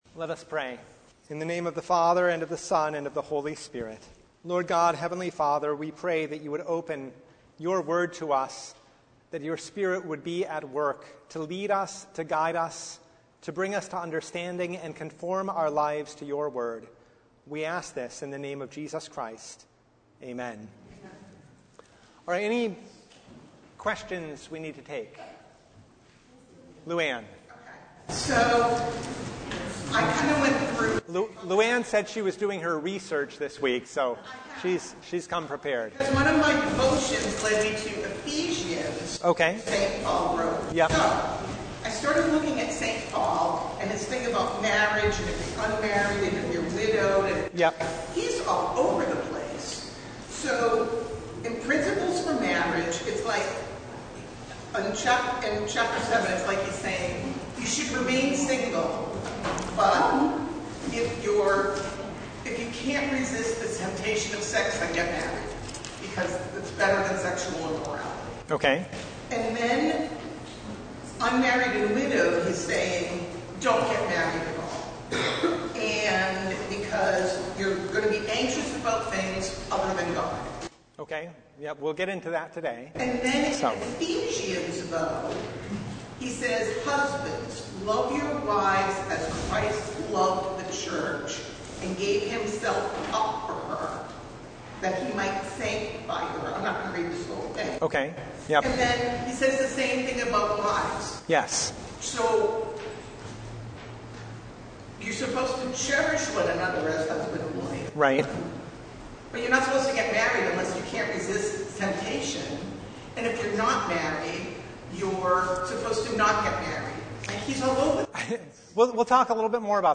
1st Corinthians 7:25-39 Service Type: Bible Hour Topics: Bible Study « Which Spirit?